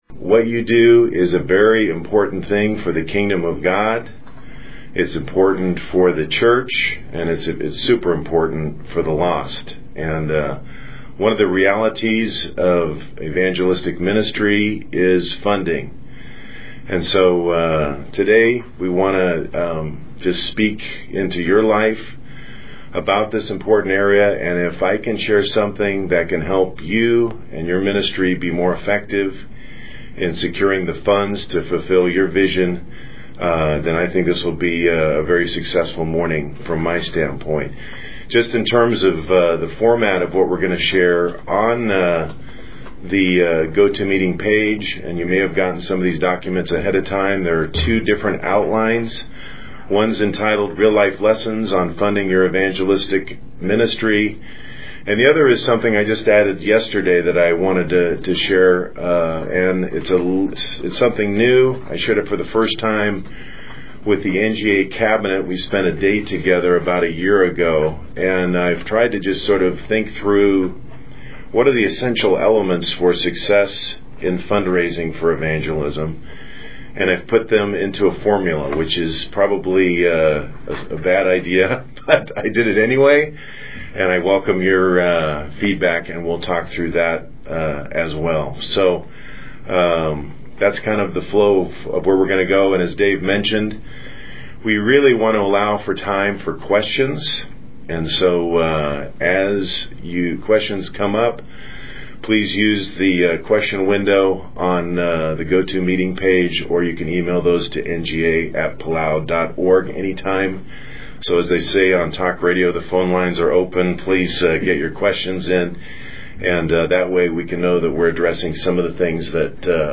Webinar